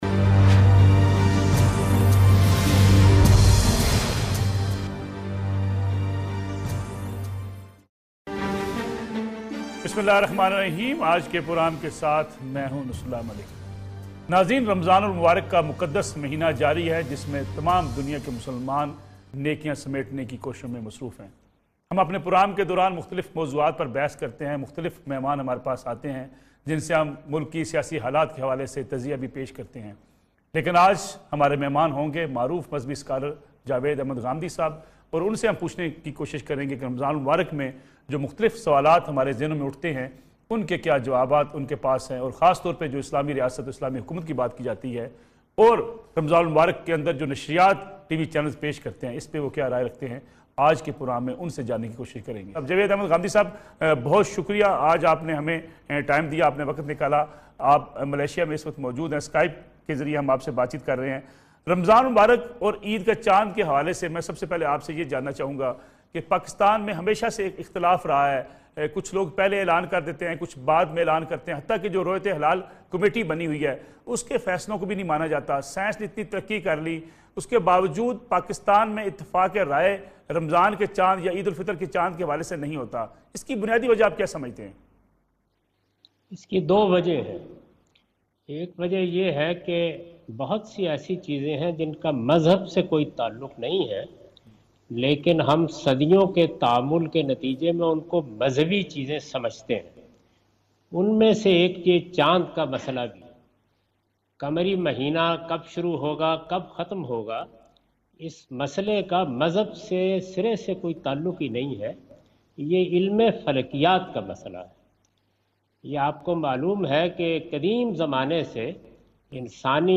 In this program Javed Ahmad Ghamidi answers the questions about miscellaneous issues on "Neo News".